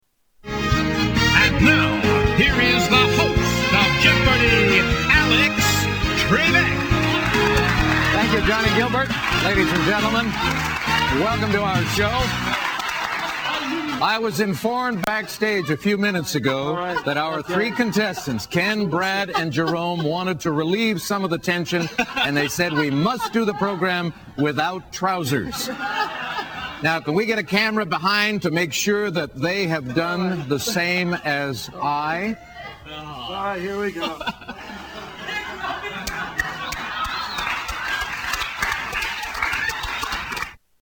Category: Television   Right: Personal
Tags: Television Alex Trebek Jeopardy Alex Trebek Audio Clips Alex Trebek drunk